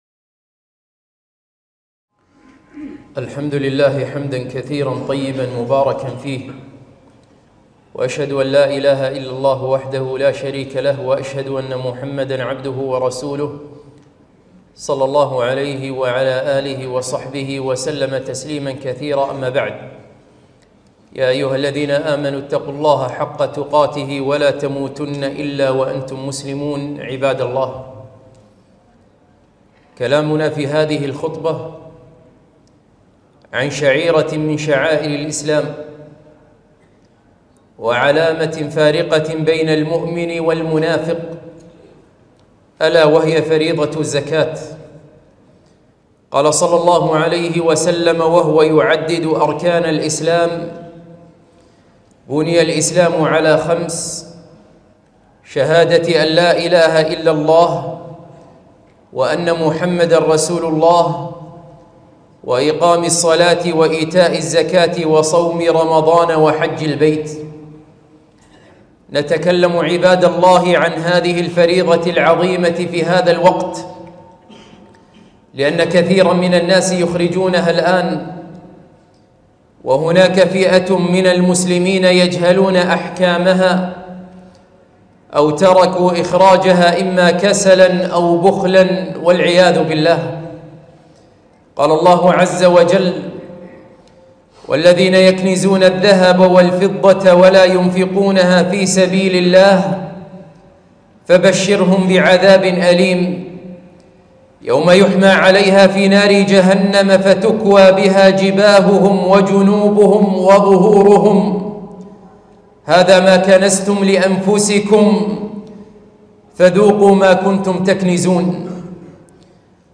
خطبة - من أحكام الزكاة الواجب معرفتها